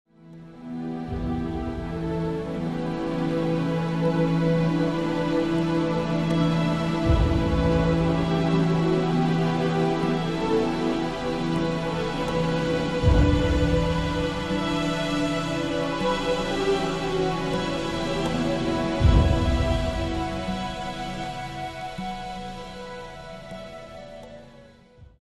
a dream-like soundtrack